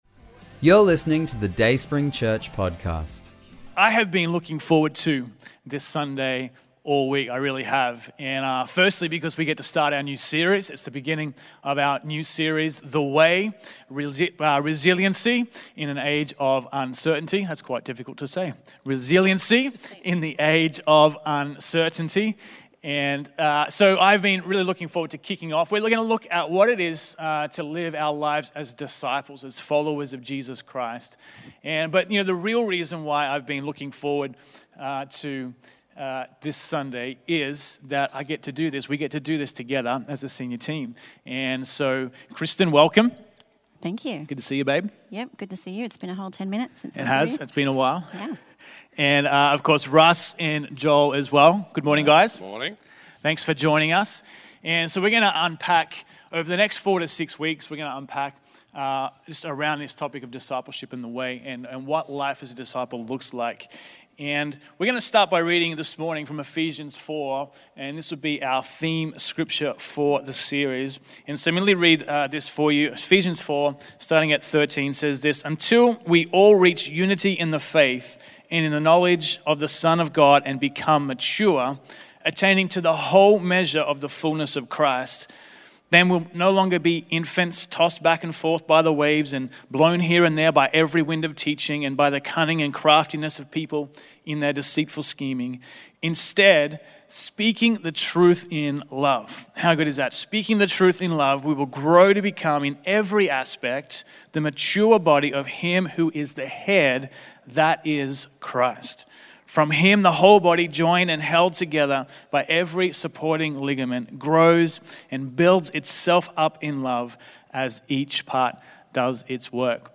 The Way - Panel
Our senior team lead us in a panel discussion on discipleship as they launch our new series "The Way" - Resiliency in a Time of Uncertainty".